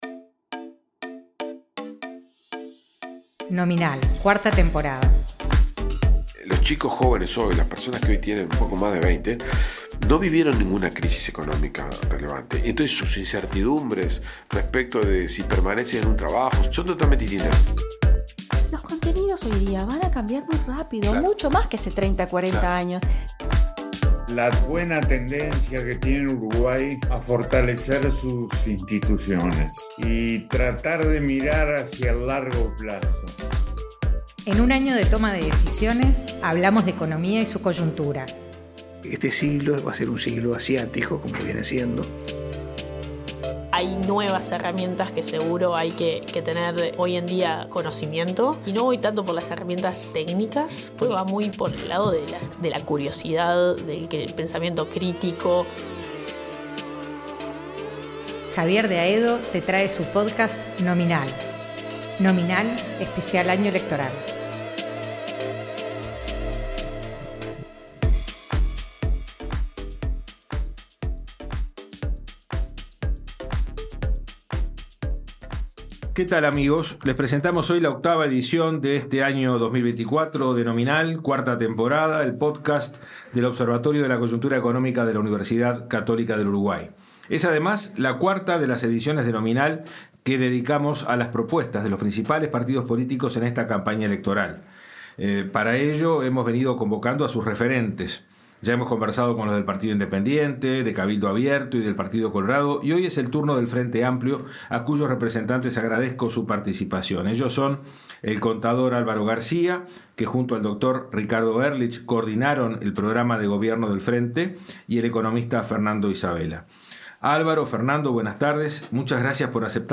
En esta nueva edición de Nominal -el podcast del Observatorio de la Coyuntura Económica- el ojo estuvo puesto en el Frente Amplio y su propuesta programática, con la entrevista